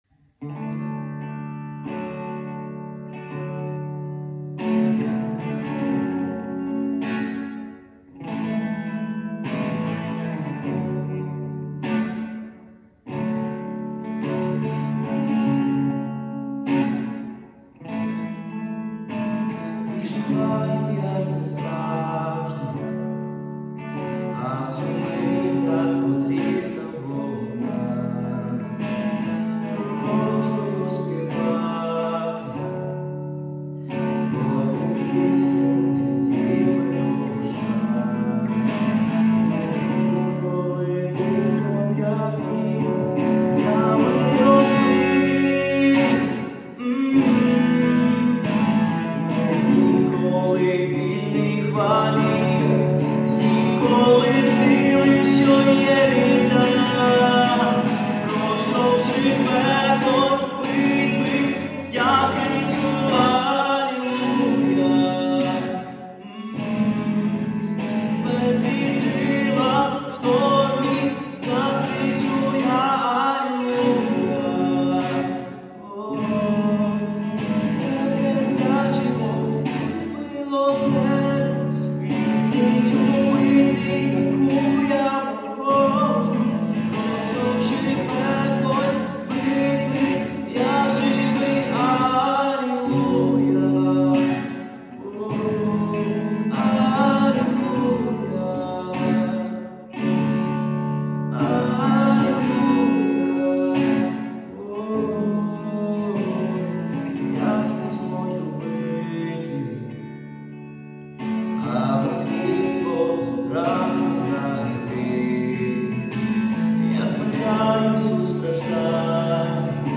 1425 просмотров 854 прослушивания 39 скачиваний BPM: 144